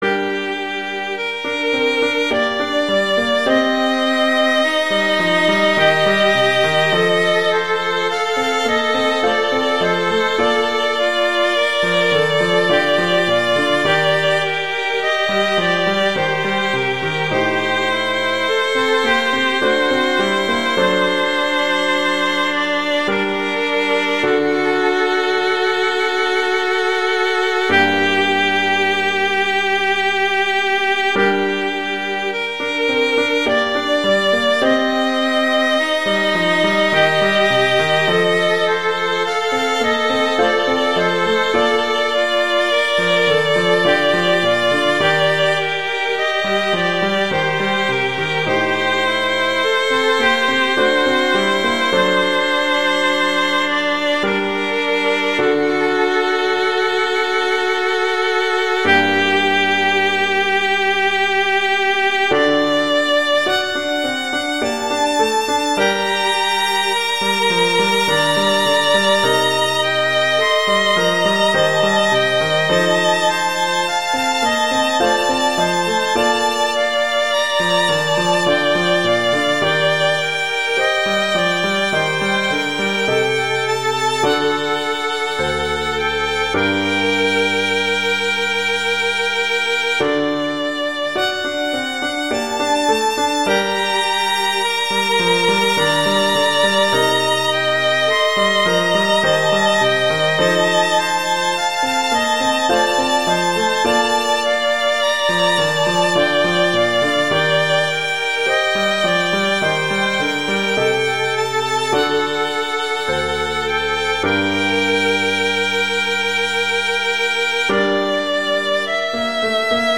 classical
G minor
Largo